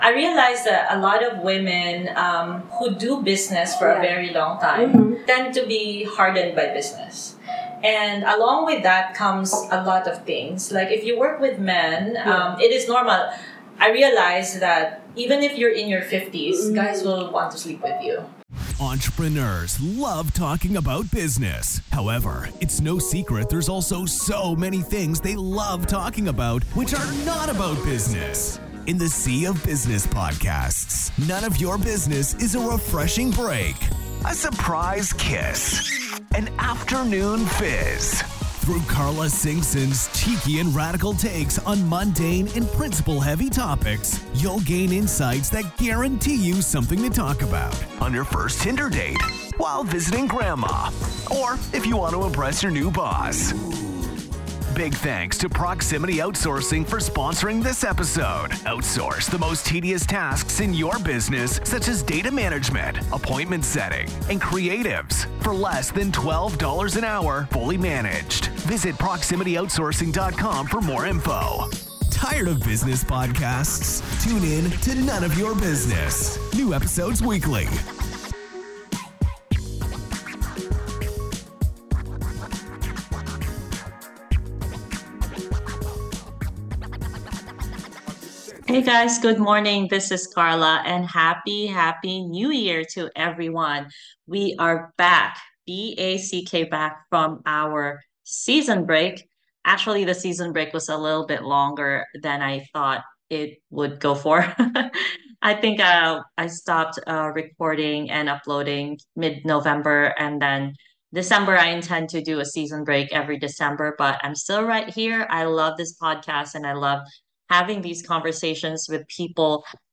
I'm recording this episode from my hometown, in Davao city Philippines